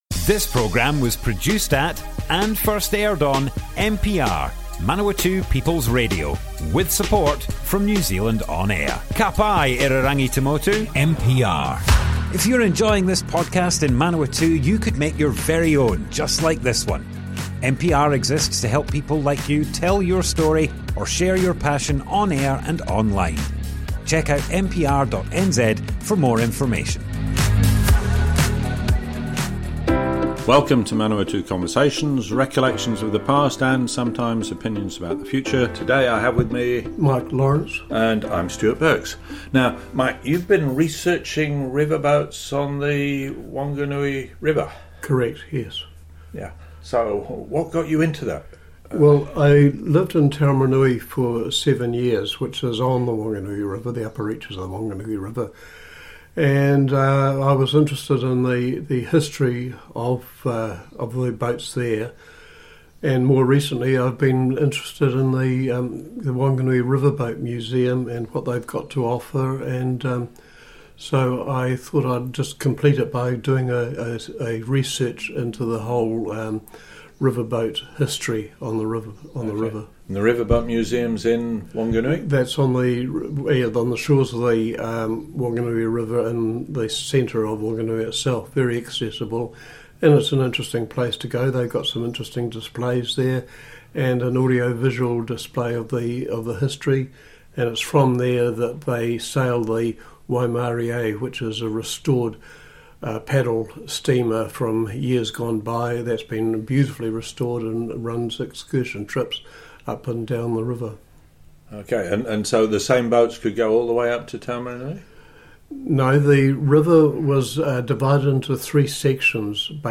Manawatu Conversations More Info → Description Broadcast on Manawatu People's Radio, 17th December 2024.
oral history